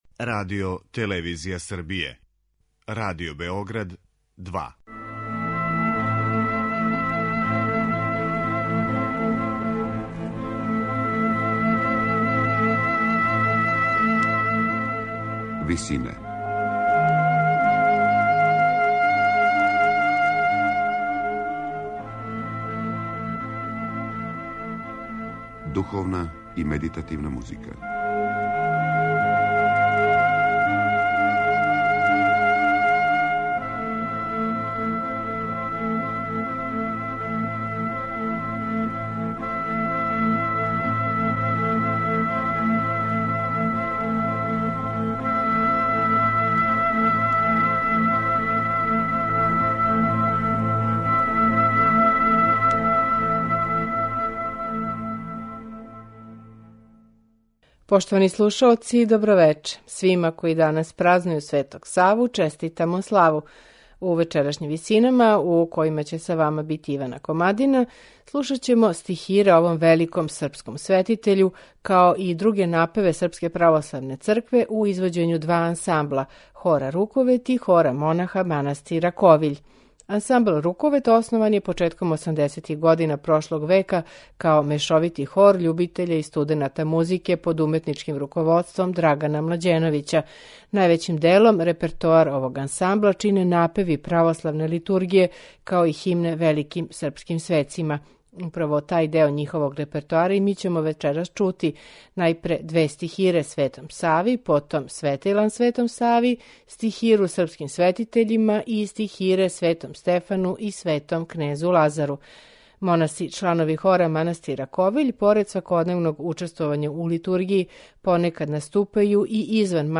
Слушаћемо стихире, светилане и химне Светом Сави, као и стихире и тропаре Светом Стефану и Светом кнезу Лазару.
Ове напеве, као и неке од фрагмената литургије Српске православне цркве, изводиће хор "Руковет" и хор манастира Ковиљ.